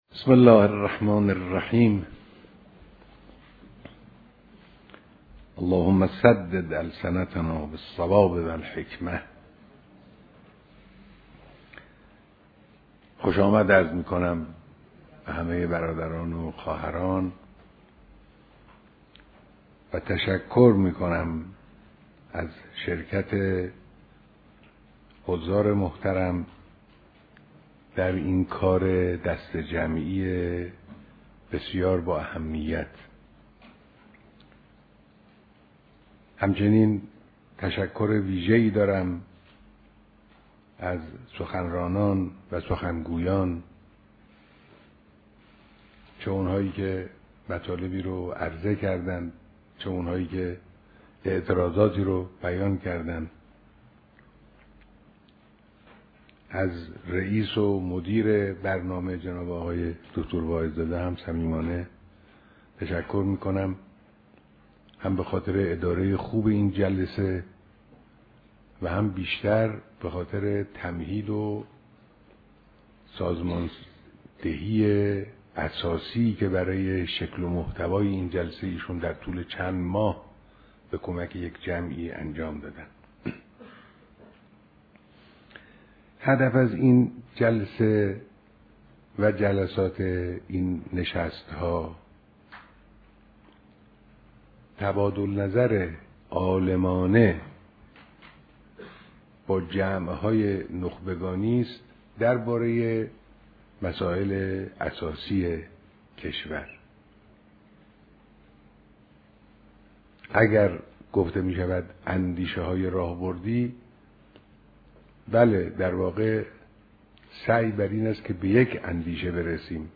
بیانات در سومین نشست اندیشه های راهبردی با موضوع زن و خانواده